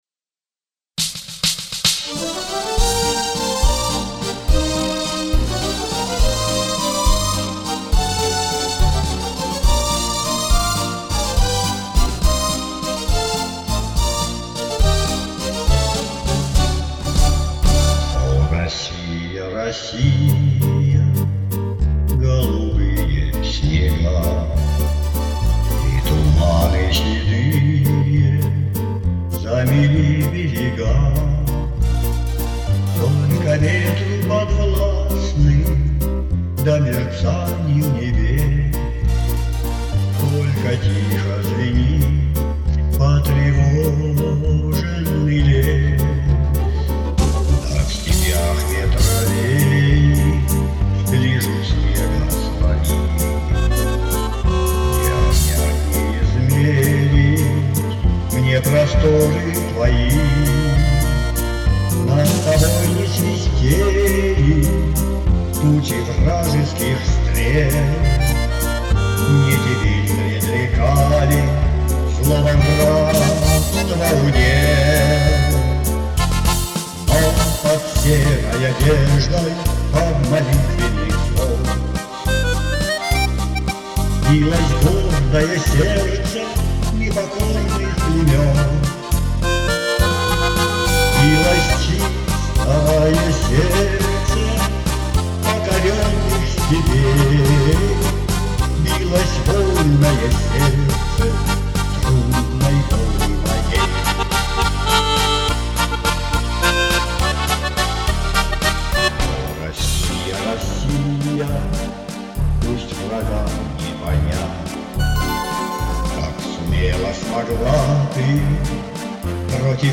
Всех поздравляет с Днем России и дарит эту песню в собственном исполнении.